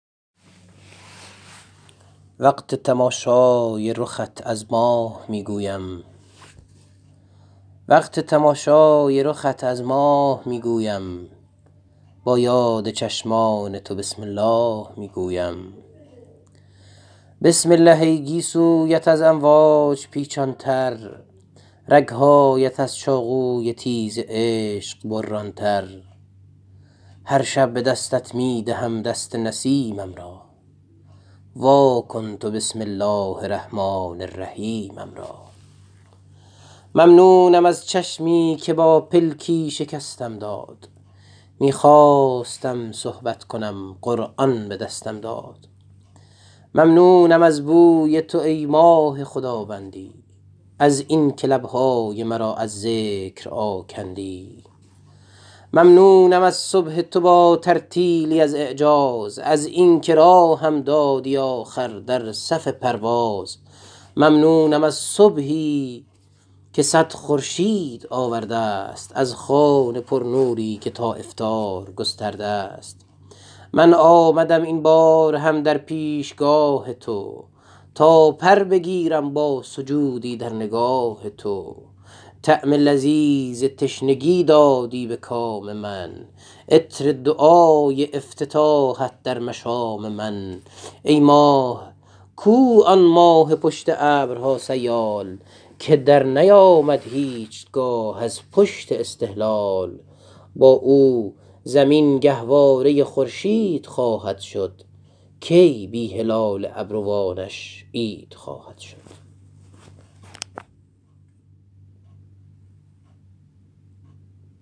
با صدای شاعر بشنوید.